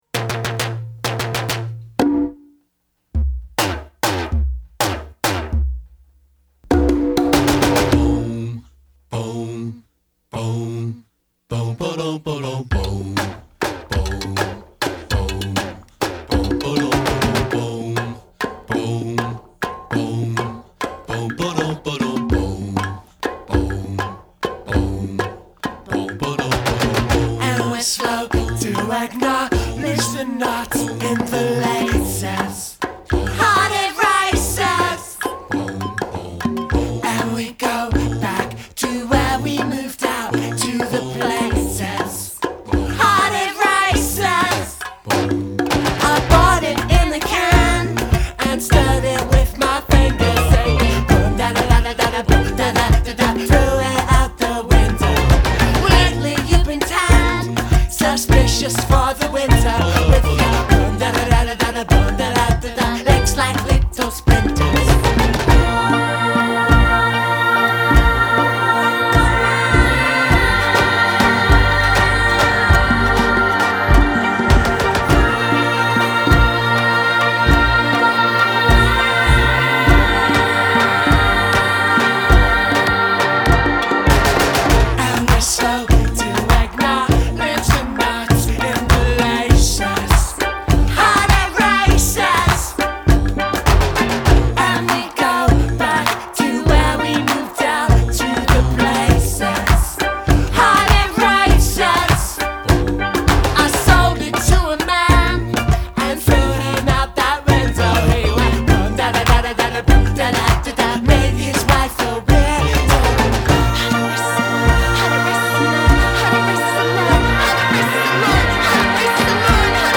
素晴らしすぎます!!!スティール・パン入りバブルガム・グライミー・ポップ。